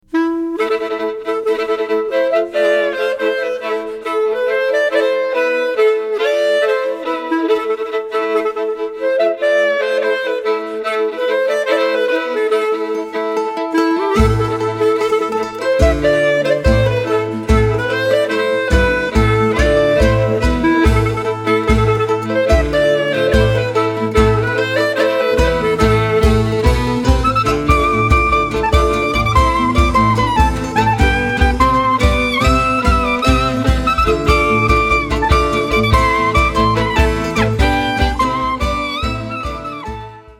Violin, accordion, vocals
Clarinet, flute, vocals
Guitar, mandoline, vocals
Doublebass,  guitar, percussion, vocals